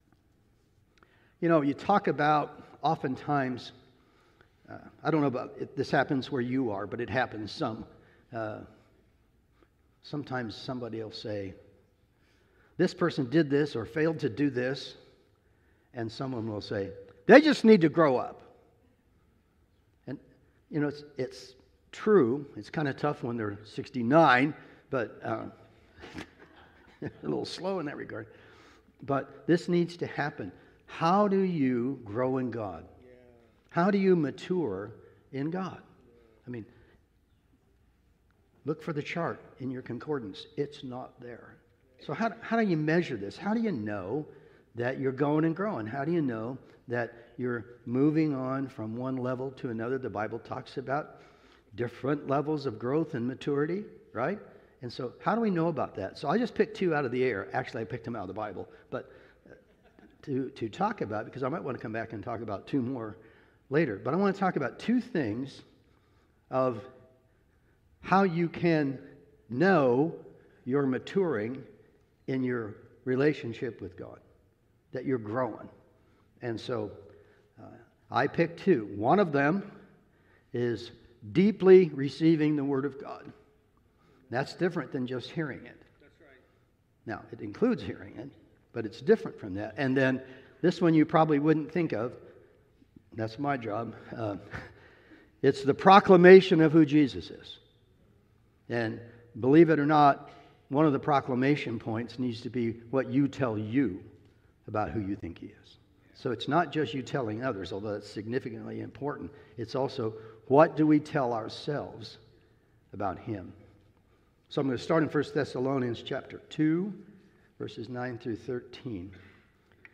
Sunday Morning Sermon Download Files Notes